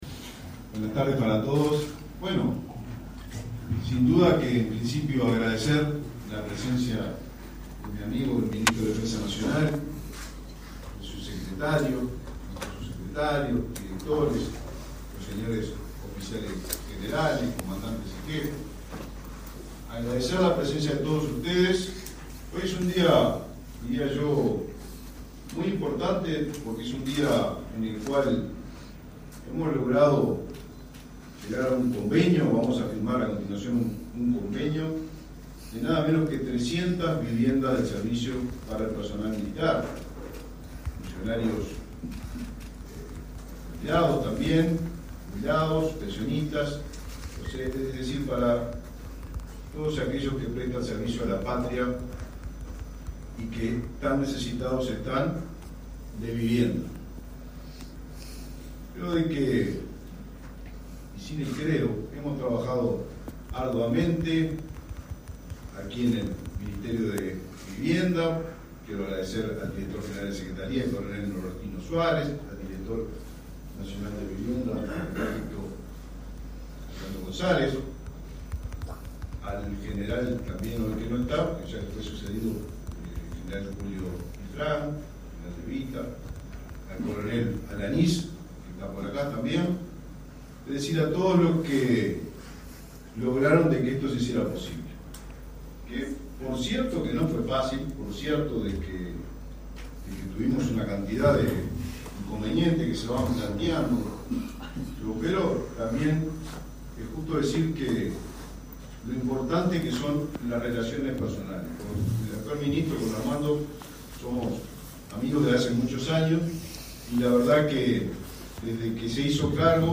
Acto de convenio entre los MVOT y MDN para beneficiar a militares en actividad y retirados
Acto de convenio entre los MVOT y MDN para beneficiar a militares en actividad y retirados 09/12/2024 Compartir Facebook X Copiar enlace WhatsApp LinkedIn El Ministerio de Vivienda y Ordenamiento Territorial (MVOT) firmó un convenio con el Ministerio de Defensa Nacional (MDN), este 9 de diciembre, para beneficar con soluciones habitacionales a militares en actividad y retirados. Participaron de la firma los ministros Raúl Lozano y Armando Castaingdebat.